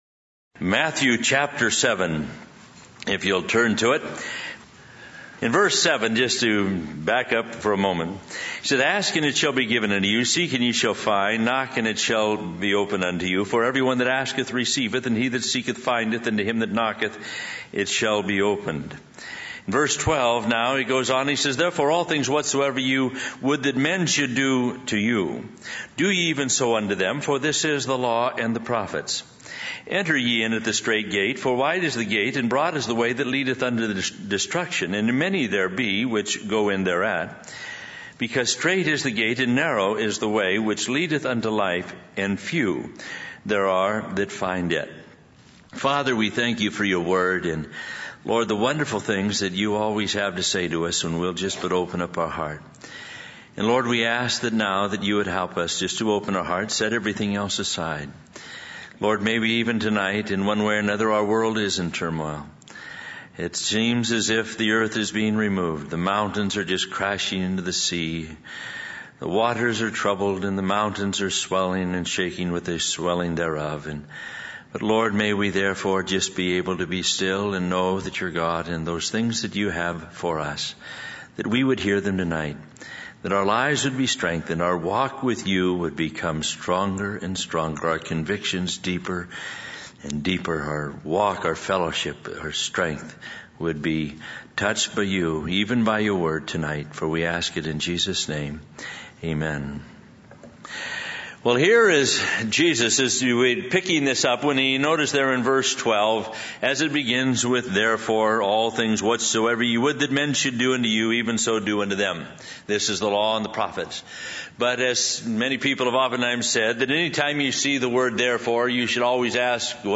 In this sermon, the preacher discusses the concept of a narrow gate and a narrow way. He explains that there are two paths in life - one that the world follows and another that those who know and trust God follow.